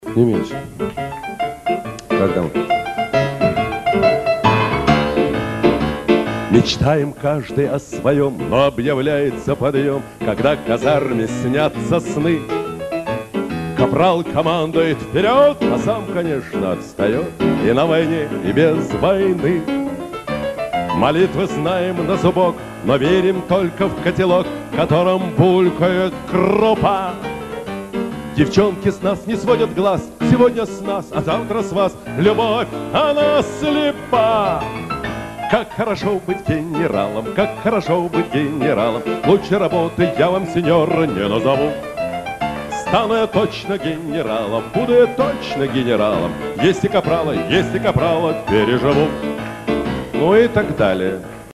правда в мусорном качестве.